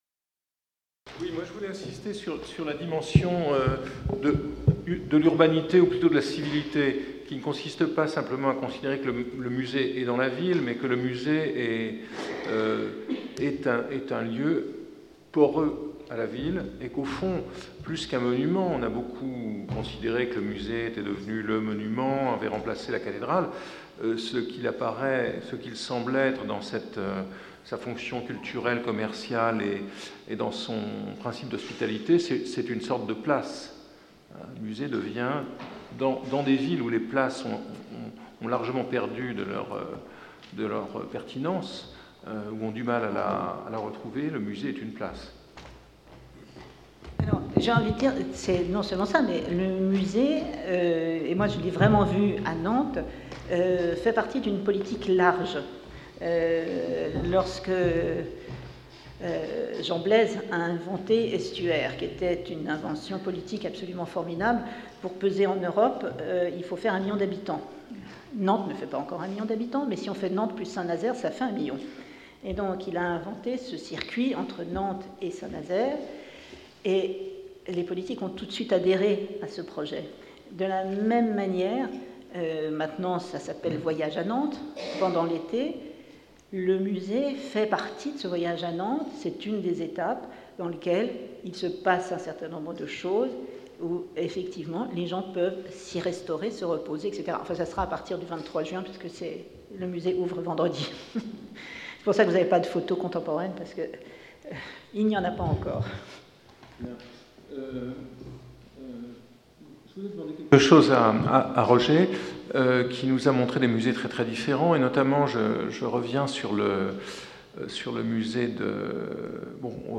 Table ronde conclusive | Collège de France
Colloque 19 Jun 2017 18:15 à 19:15 Jean-Louis Cohen Table ronde conclusive Suivant Voir aussi Jean-Louis Cohen, chaire Architecture et forme urbaine Le musée contemporain : stratégies, espaces, esthétiques